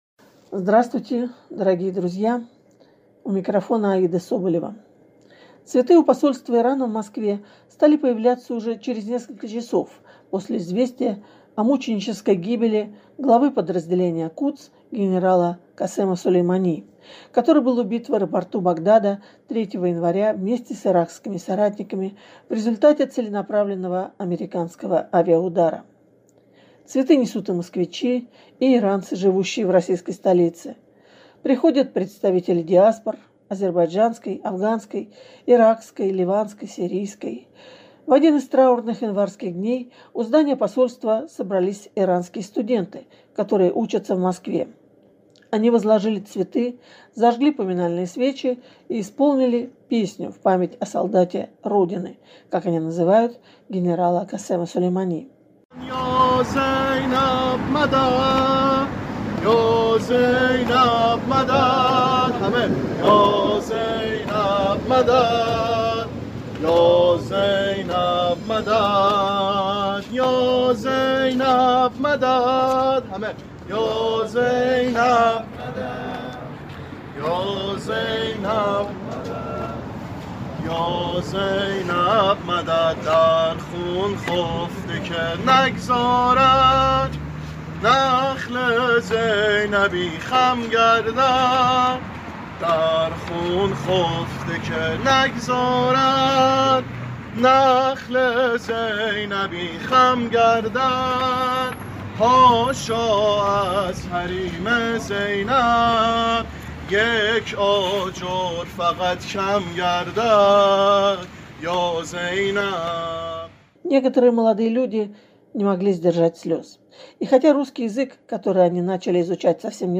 В один из траурных январских дней у здания Посольства собрались иранские студенты, которые учатся в Москве.
И хотя русский язык, который они начали изучать совсем недавно, дается им нелегко, несколько человек решились поделиться своими чувствами на наш микрофон: – Этот человек очень популярен в Иране, его очень-очень любят.